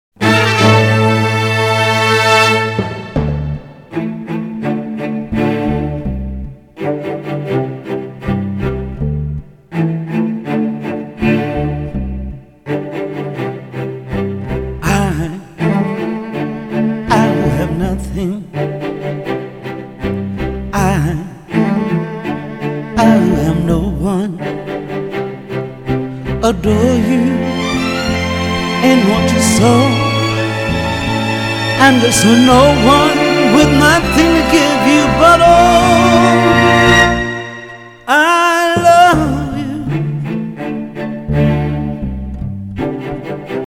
• Качество: 320, Stereo
мужской вокал
спокойные
скрипка